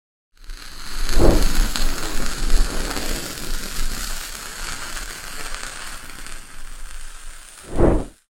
Melting Toy 🗽 ASMR Sound Effects Free Download